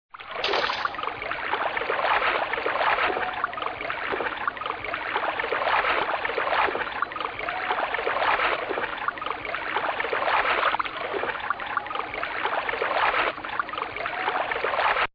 Wasser
Plätschernd, tropfend, blubbernd, sprudelnd, rieselnd, rauschend, prasselnd, trommelnd, peitschend, grollend, donnernd, dröhnend, schäumend, klatschend, brandend, gitschend, stiebend - wie kann sich ein und das selbe Element je nach Situation nur dermaßen unterschiedlich anhören?
wasser.mp3